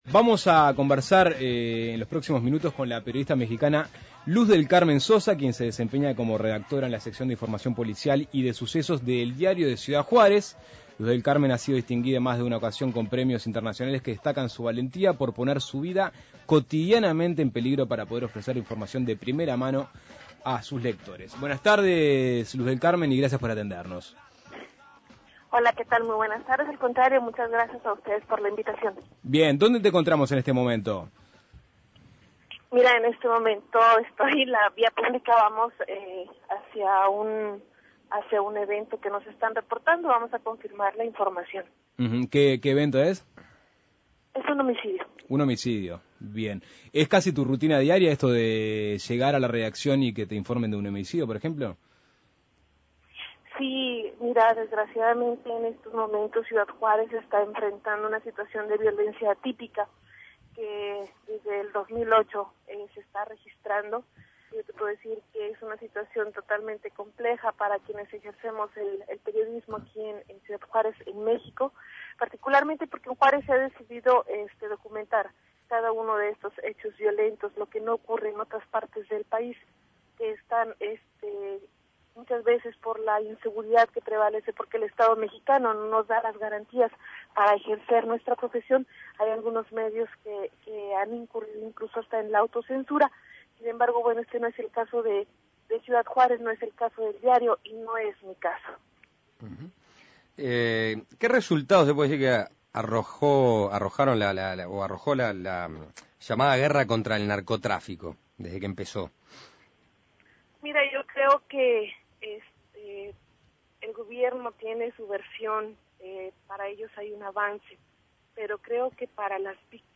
La crudeza de Ciudad Juárez en la voz de una periodista
Suena Tremendo dialogó con la periodista mexicana